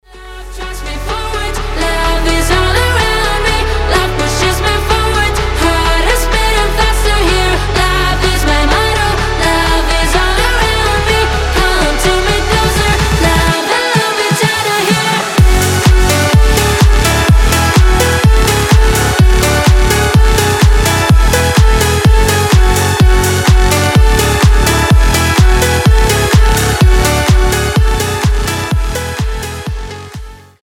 танцевальные , зажигательные , house